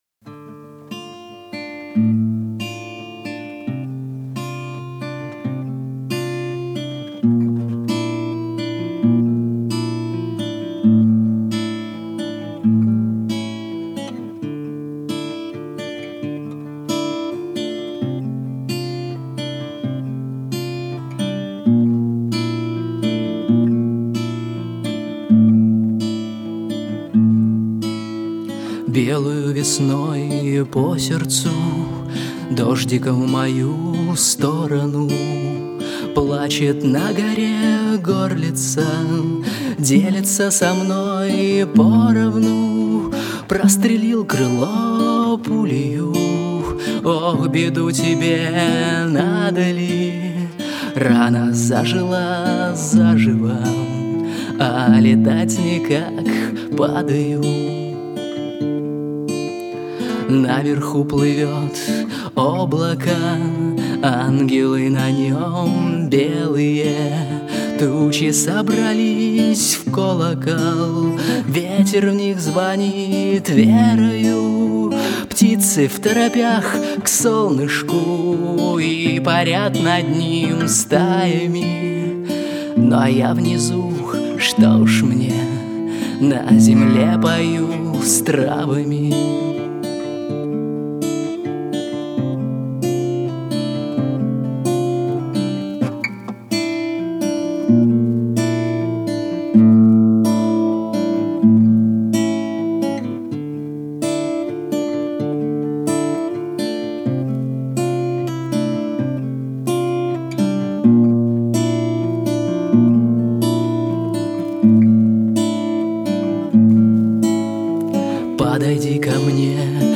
У нас в гостях автор-исполнитель